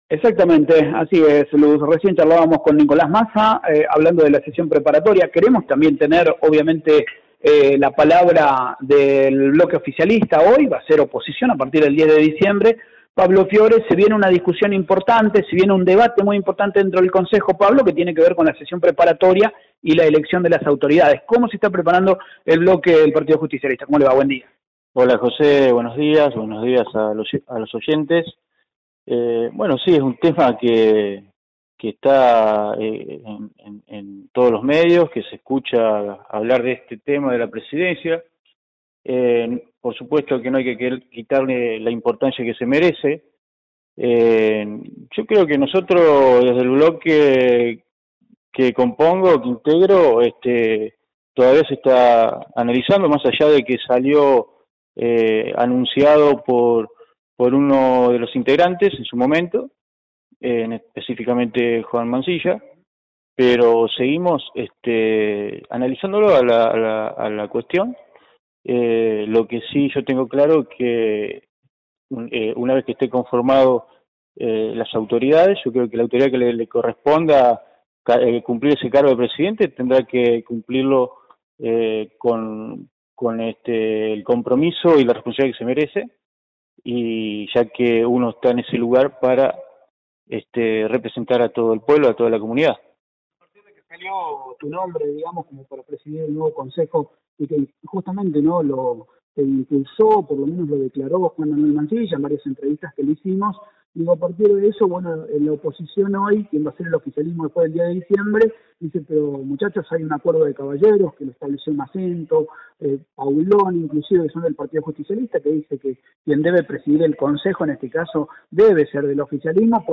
El Concejal Pablo Fiore habló en Radio Eme Ceres y fue prudente en sus declaraciones dejando en claro, que a quien le toque llevar adelante la presidencia del Concejo, deberá hacerlo con responsabilidad.
pablo-fiore.mp3